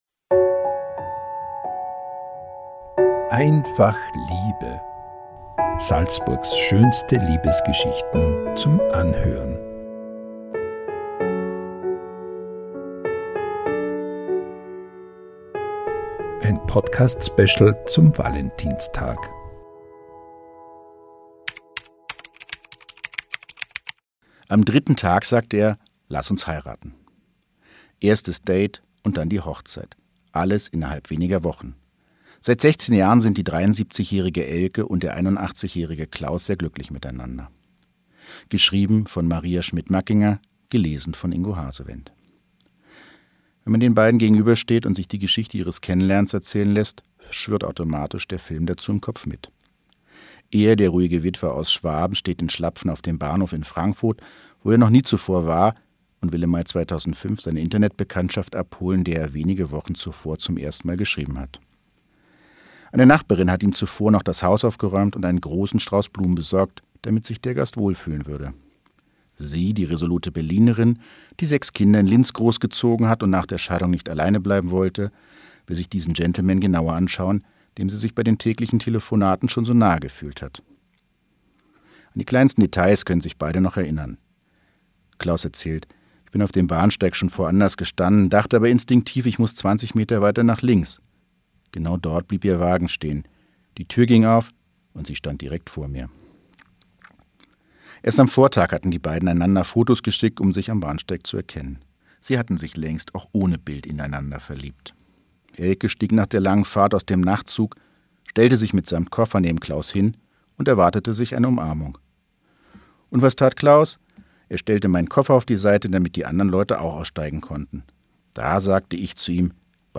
Pünktlich zum Valentinstag lesen SN-Redakteurinnen und Redakteure Salzburgs schönste Liebesgeschichten ein.